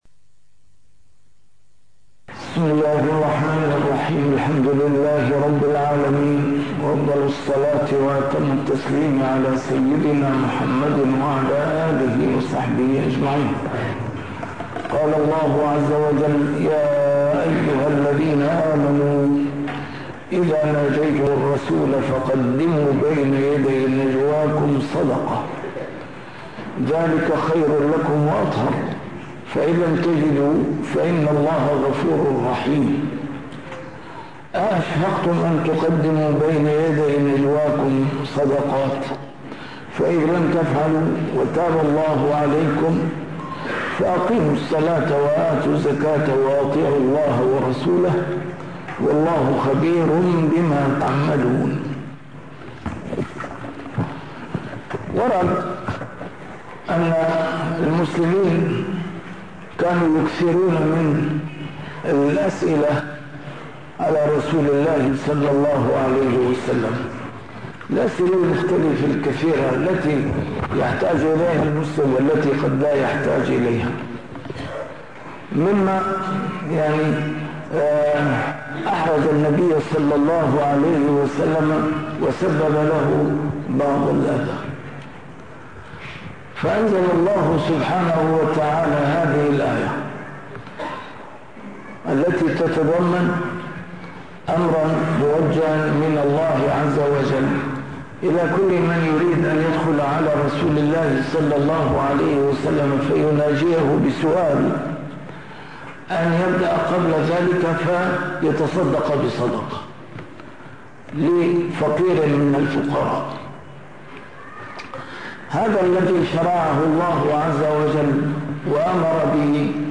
A MARTYR SCHOLAR: IMAM MUHAMMAD SAEED RAMADAN AL-BOUTI - الدروس العلمية - تفسير القرآن الكريم - تسجيل قديم - الدرس 761: المجادلة 12-13